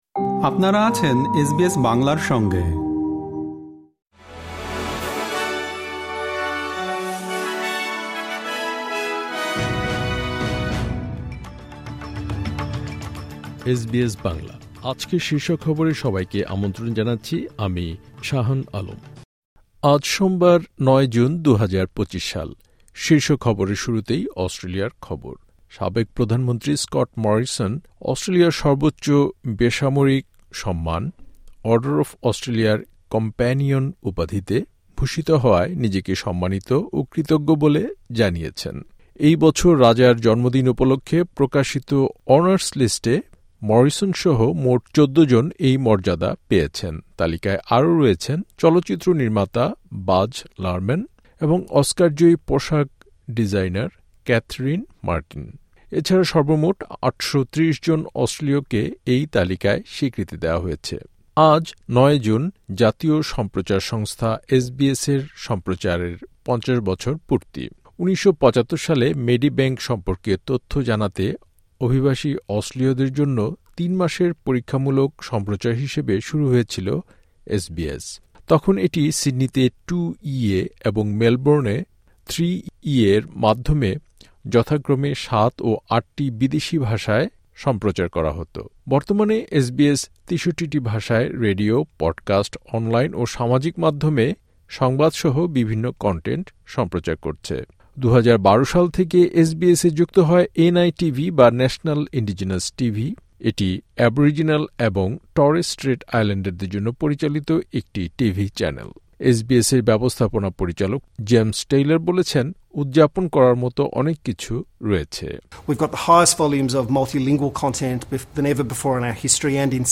এসবিএস বাংলা শীর্ষ খবর: ৯ জুন, ২০২৫